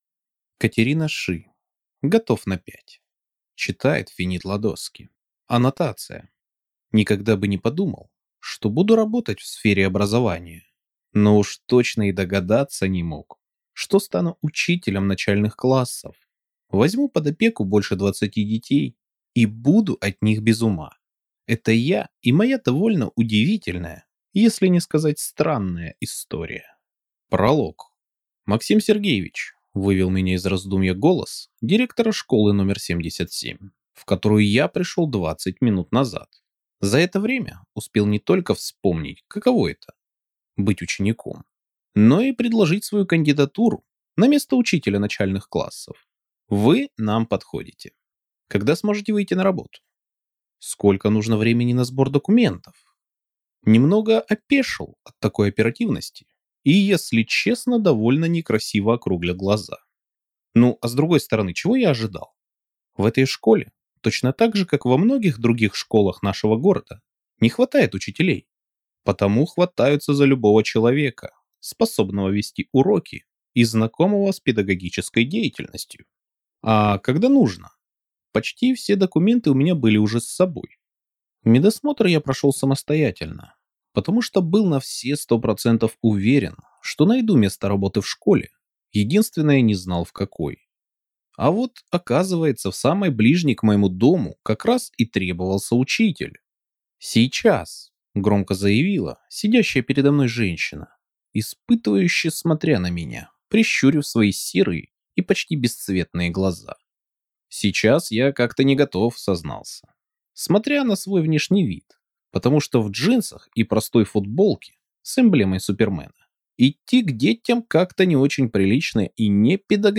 Aудиокнига Готов на пять